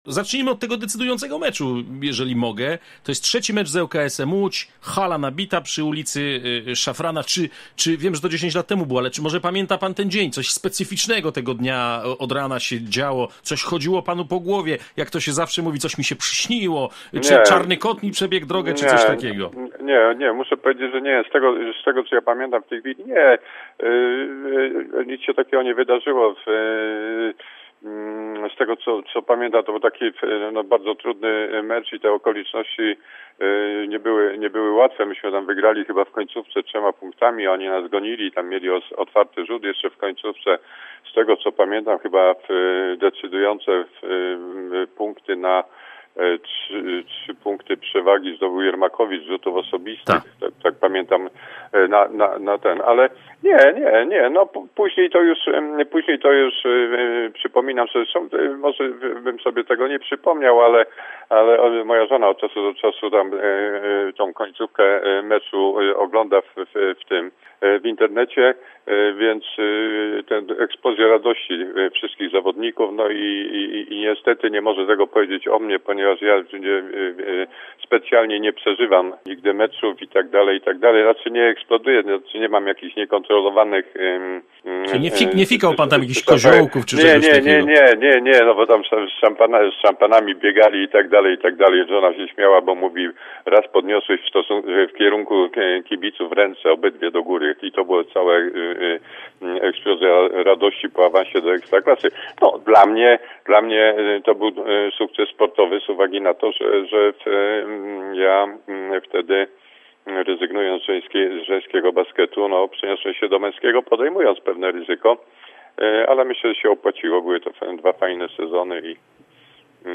rozmowie z Radiem Zielona Góra